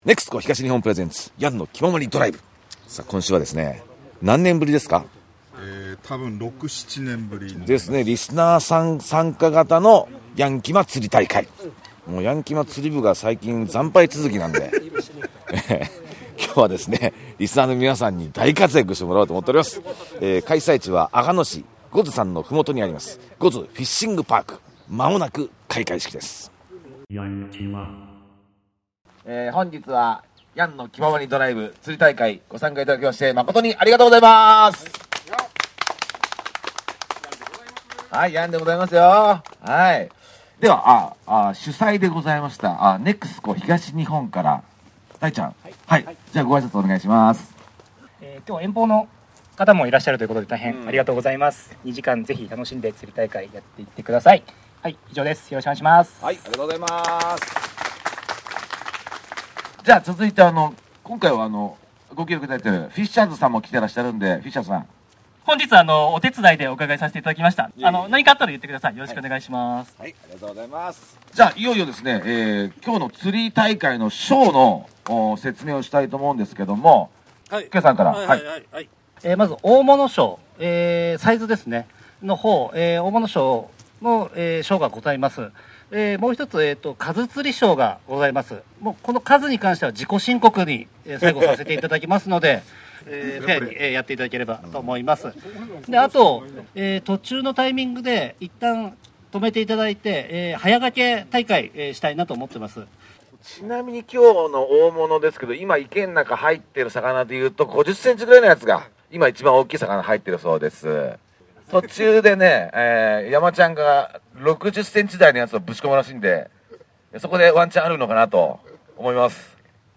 今回の釣り場は、五頭山のふもとにあります「五頭フィッシングパーク」
みんないっせいにルアーを投入！
バシャ！バシャ！